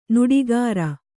♪ nuḍigāra